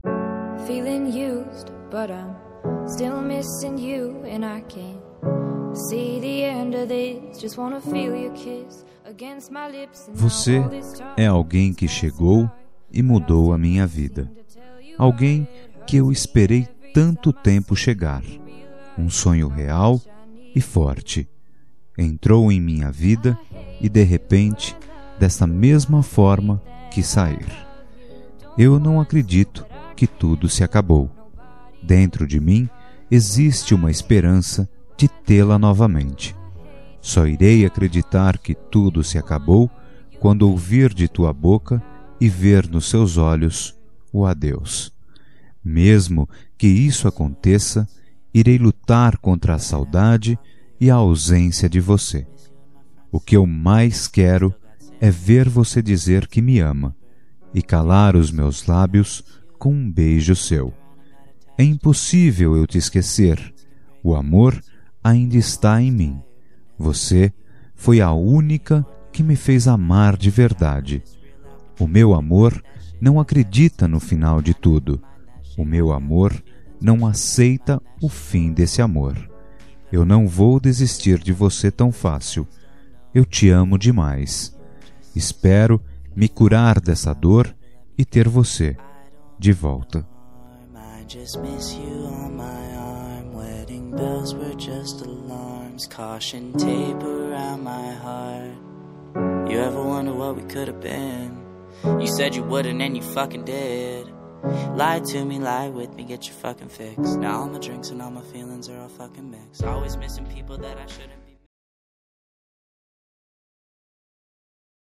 Telemensagem de Reconciliação Romântica – Voz Masculina – Cód: 20